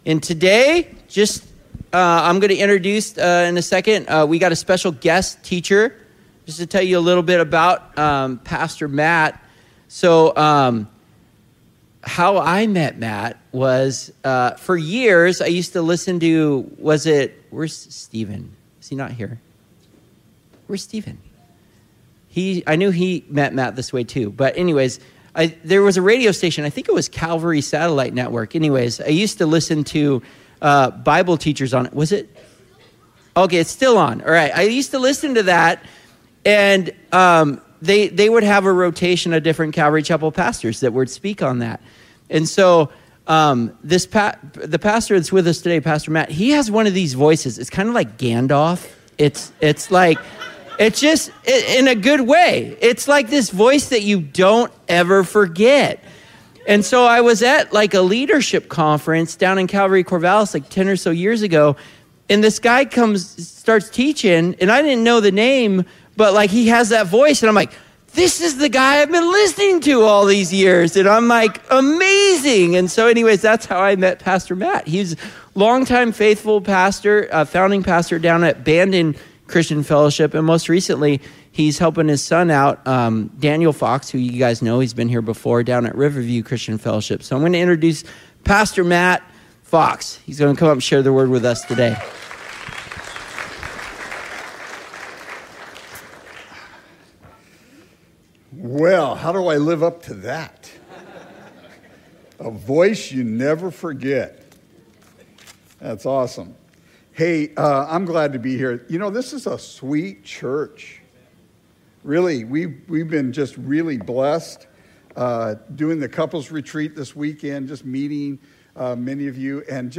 Sermons | Coastline Christian Fellowship